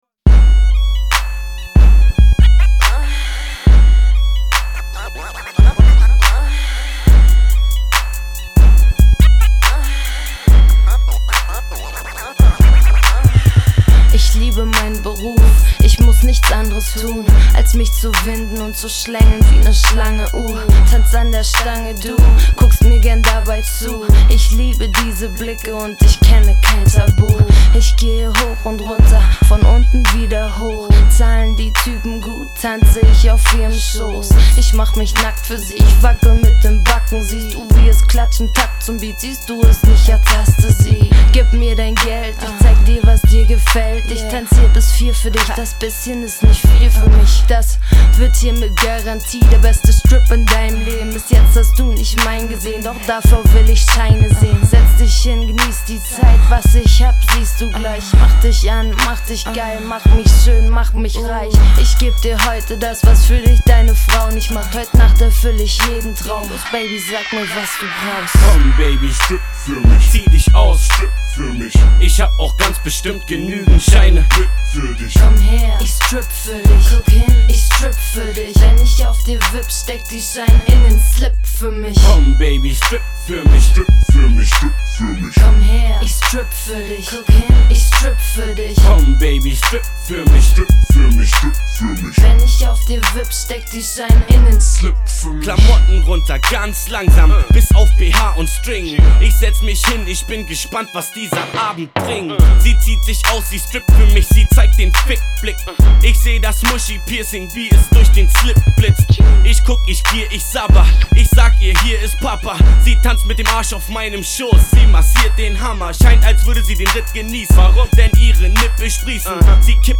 Hip Hop GER